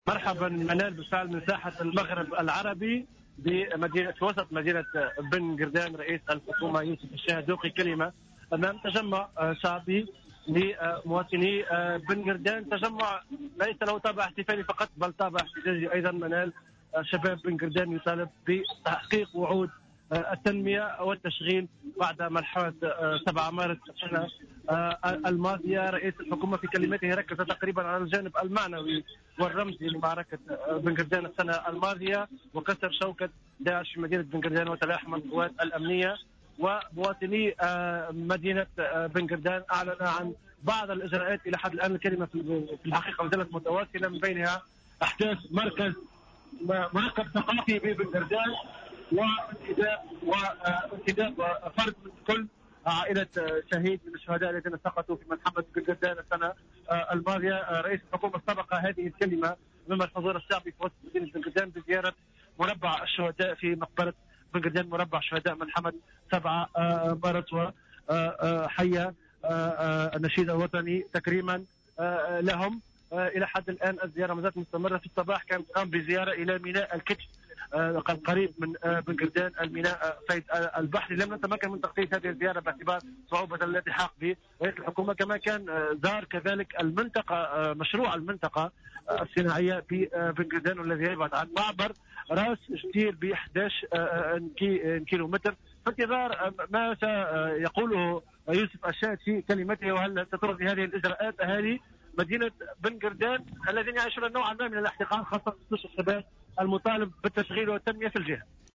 Le chef du gouvernement, Youssef Chahed a présidé aujourd'hui, en présence du ministre de la Défense nationale Farhat Horchani, la cérémonie de commémoration des événements de Ben Guerdane.